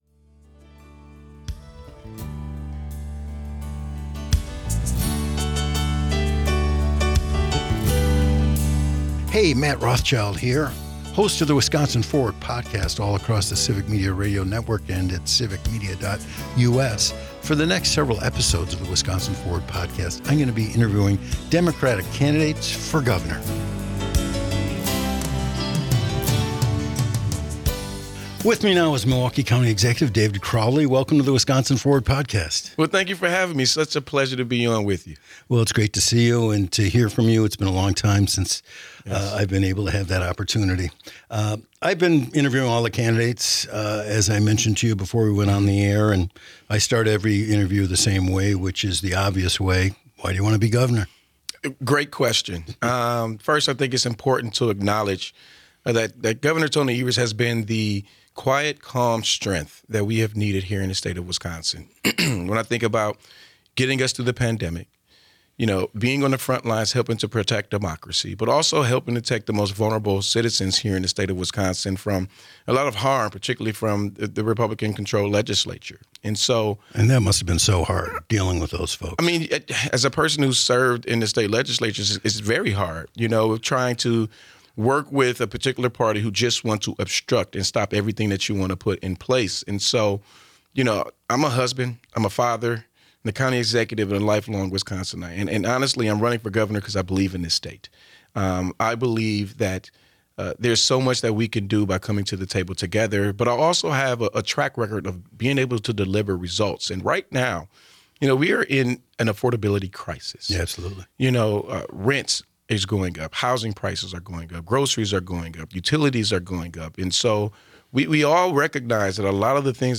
An Interview with David Crowley - Civic Media